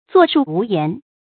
坐樹無言 注音： ㄗㄨㄛˋ ㄕㄨˋ ㄨˊ ㄧㄢˊ 讀音讀法： 意思解釋： 見「坐樹不言」。